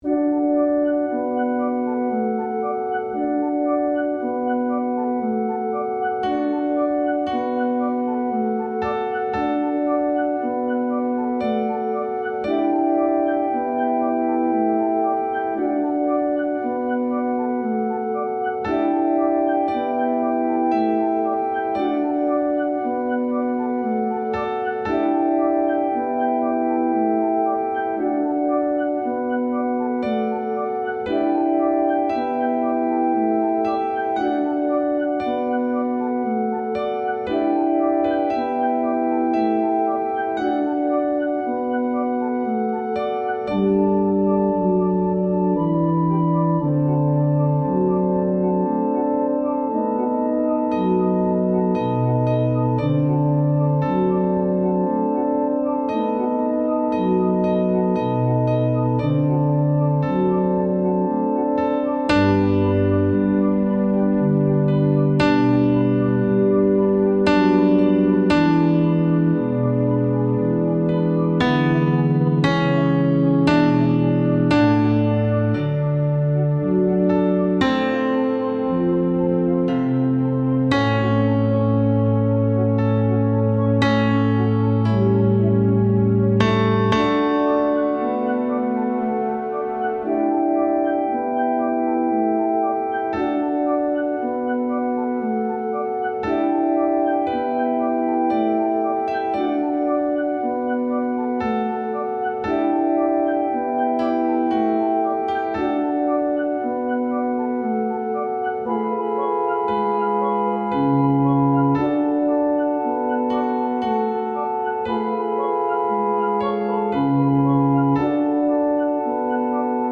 Tenor I
Mp3 Música